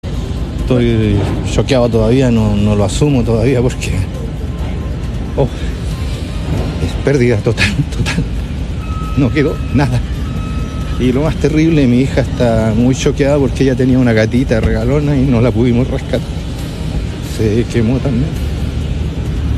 En este sentido, uno de los damnificados contó a Radio Bío Bío el momento en que se enteraron del incendio y los minutos posteriores.
“Los mas terrible es que mi hija tenía una gatita y no pudimos rescatarla… murió quemada”, relató el hombre entre sollozos.